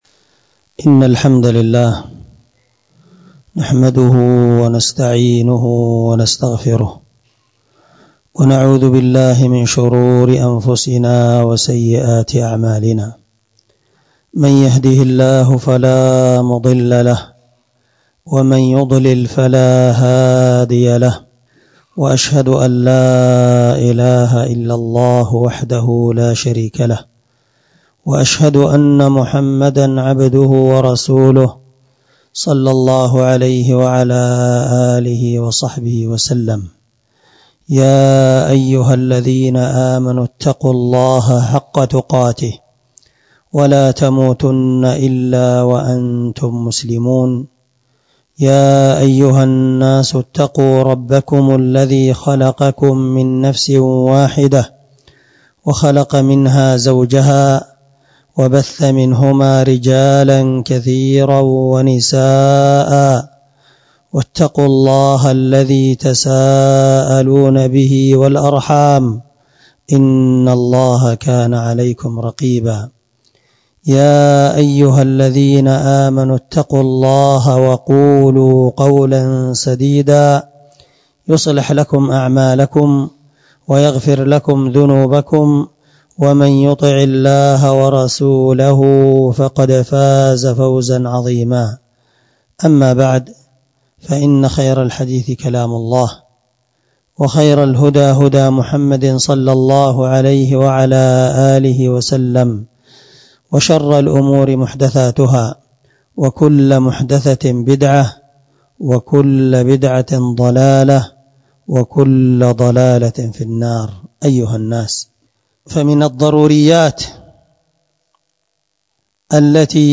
خطبة جمعة بعنوان (التحذير من السرقة والمال الحرام